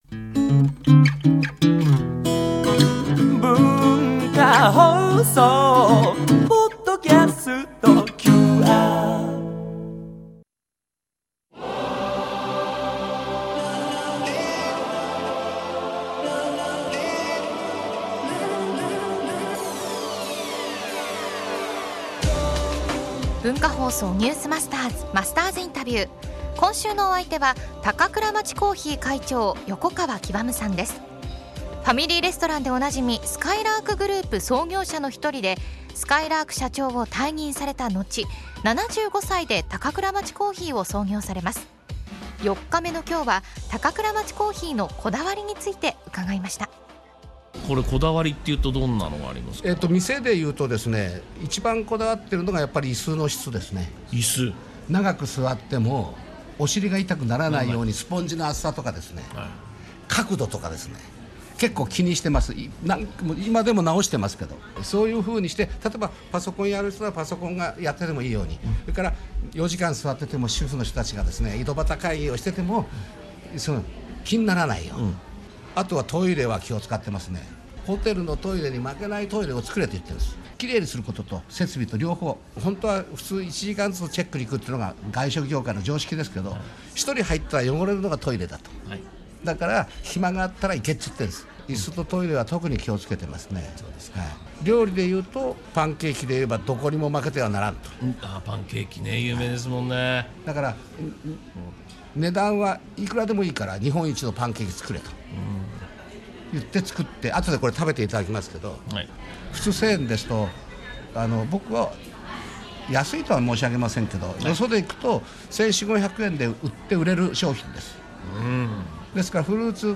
毎週、現代の日本を牽引するビジネスリーダーの方々から次世代につながる様々なエピソードを伺っているマスターズインタビュー。
（月）～（金）AM7：00～9：00　文化放送にて生放送！